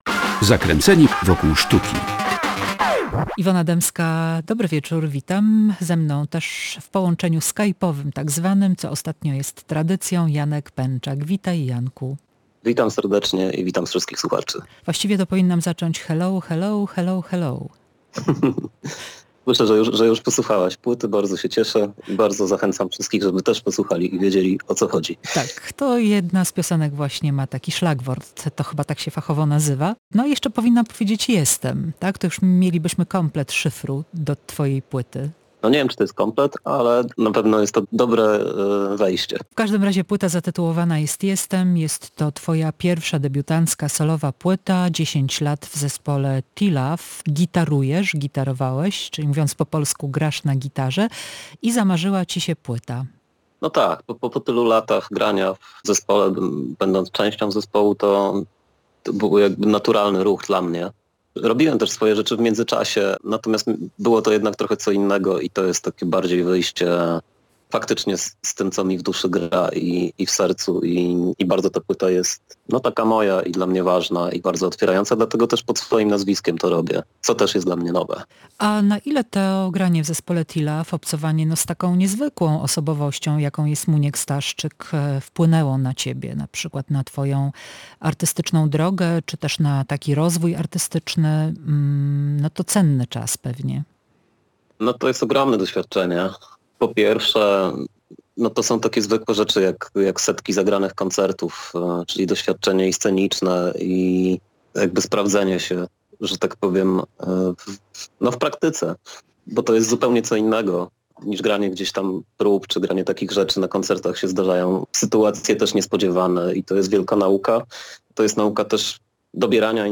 Dobra płyta na złe czasy – rozmowa